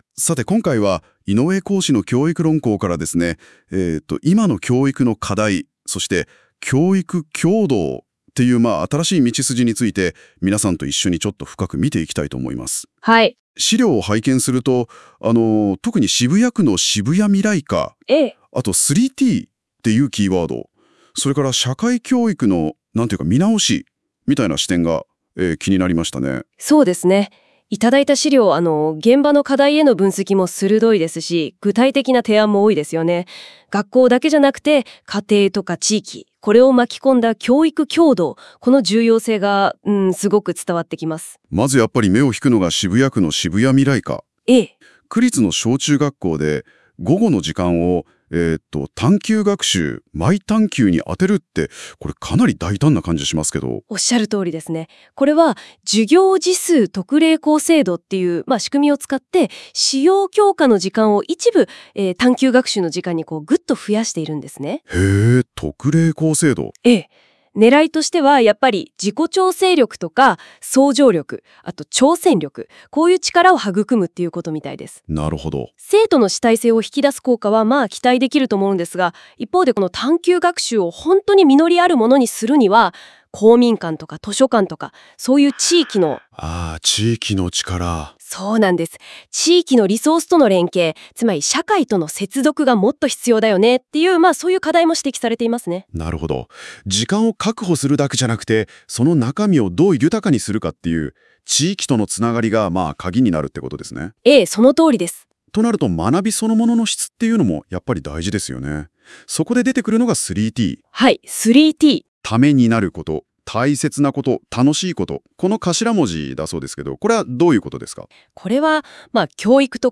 ただし、固有名詞の読み間違い等がそれなりにあり、やはり機械（AI）なのだ　なあと、独り苦笑いしています！
音声解説 　〇概要（略）